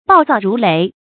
暴躁如雷 bào zào rú léi
暴躁如雷发音
成语注音 ㄅㄠˋ ㄗㄠˋ ㄖㄨˊ ㄌㄟˊ